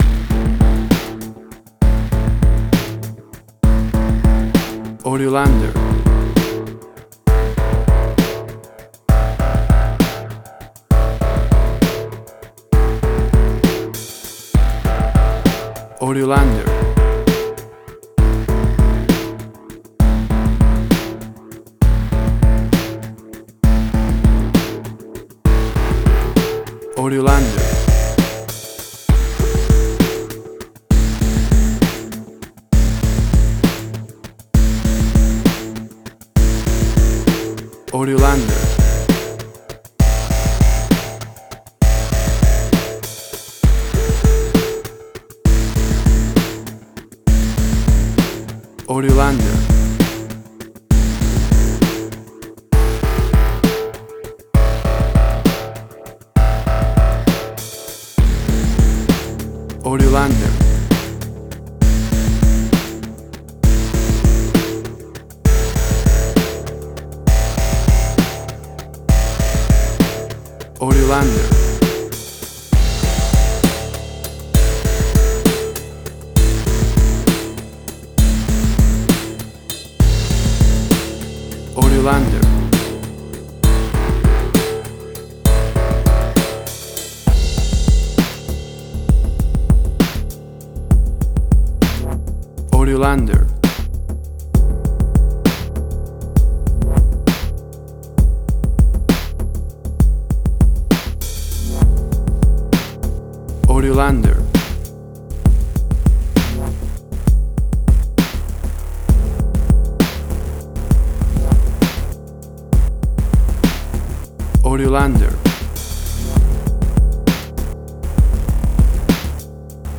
Suspense, Drama, Quirky, Emotional.
Electro rock, anger, intense.
WAV Sample Rate: 16-Bit stereo, 44.1 kHz
Tempo (BPM): 66